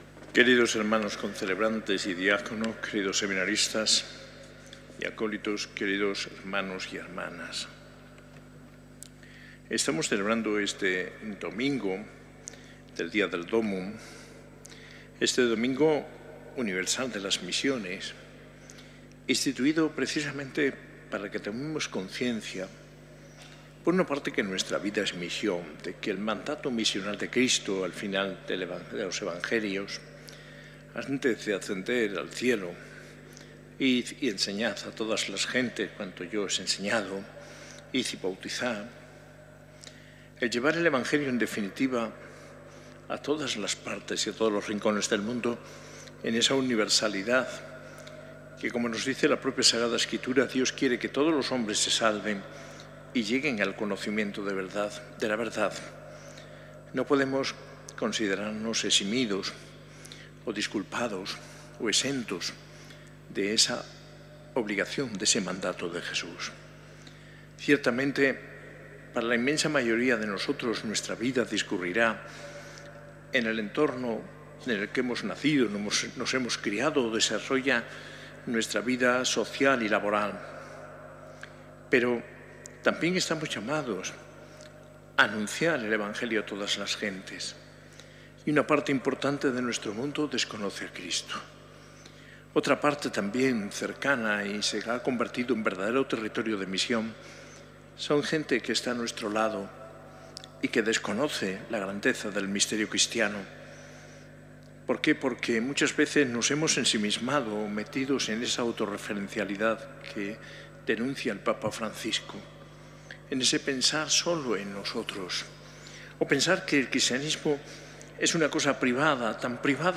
Homilía de D. José María Gil Tamayo, arzobispo de Granada, en la Eucaristía del XXIX Domingo del Tiempo Ordinario, celebrada en la S.A.I Catedral el 20 de octubre de 2024.